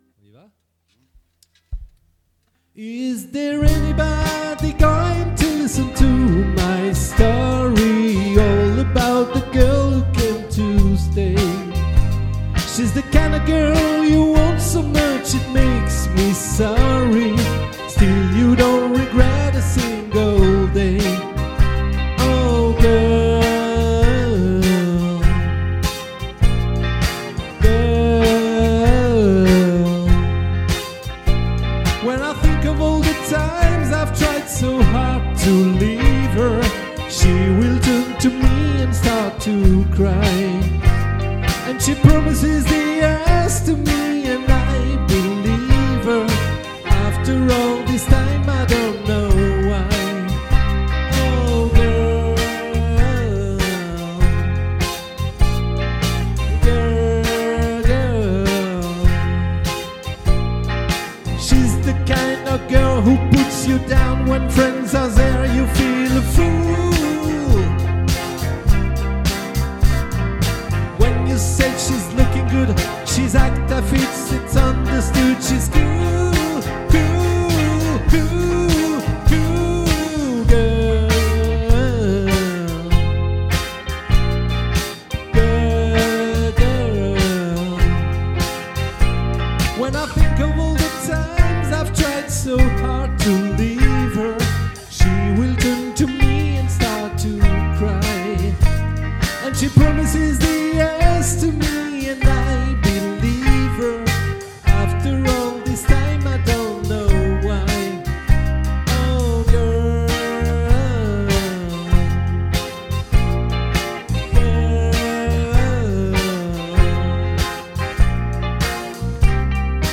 🏠 Accueil Repetitions Records_2025_10_06